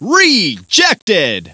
rejected.wav